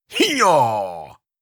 Effort Sounds
15. Effort Grunt (Male).wav